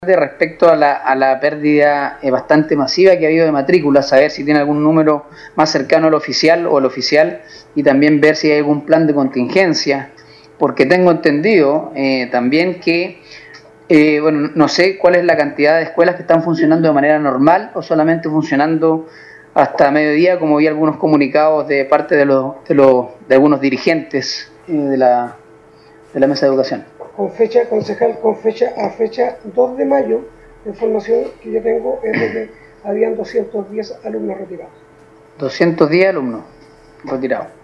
16-CONCEJAL-SAMUEL-MANDIOLA-2.mp3